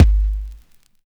Kick (6).wav